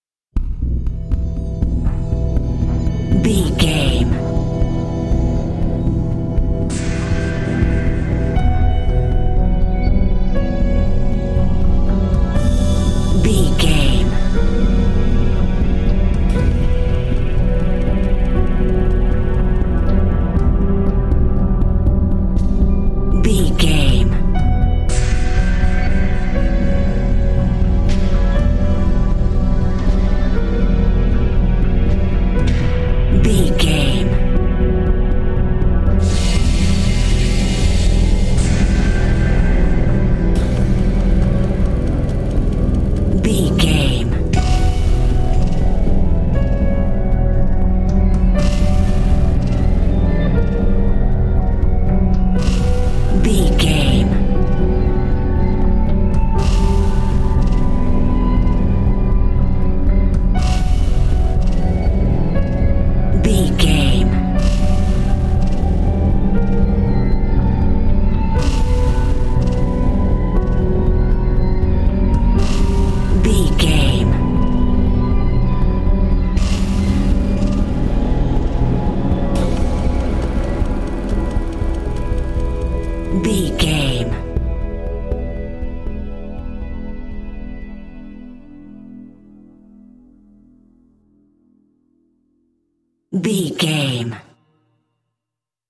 Thriller
Aeolian/Minor
piano
synthesiser
percussion
tension
ominous
dark
suspense
haunting
creepy